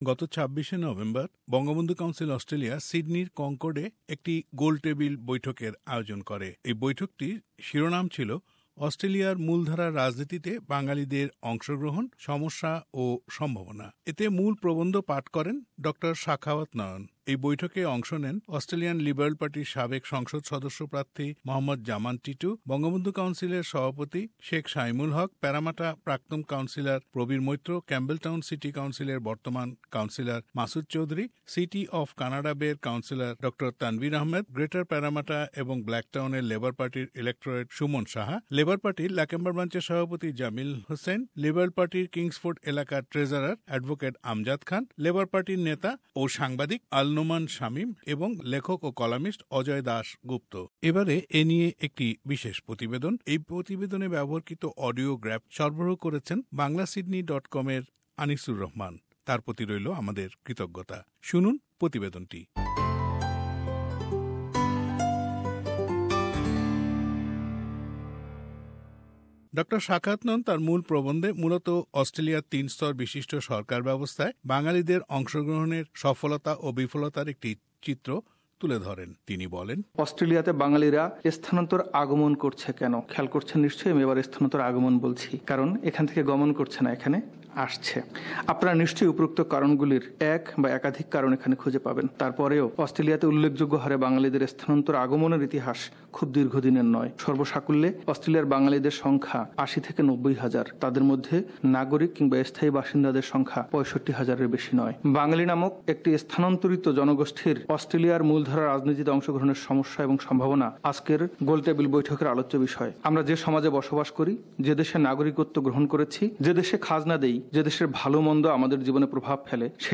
Round Table Conference : Bengali Community in Australian Politics
Round Table Conferenece organised by Bangabandhu Council Australia Source: SBS Bangla